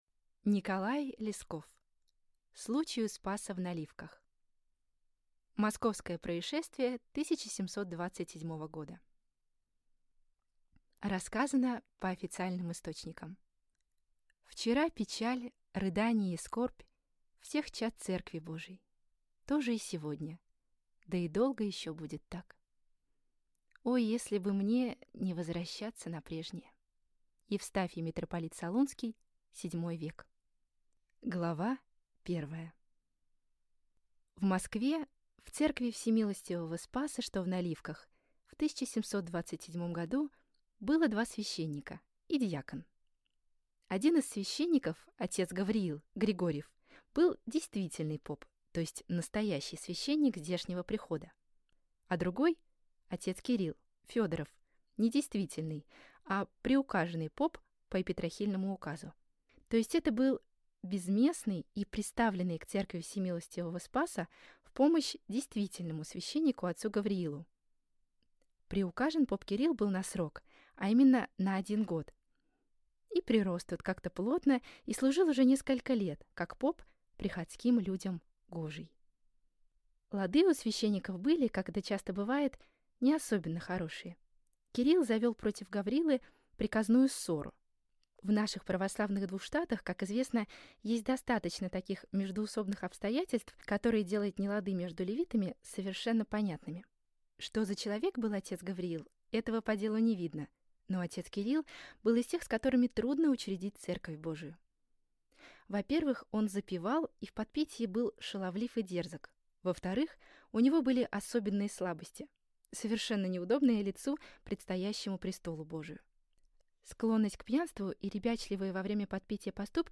Аудиокнига Случай у Спаса в Наливках | Библиотека аудиокниг